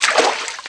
STEPS Water, Walk 01.wav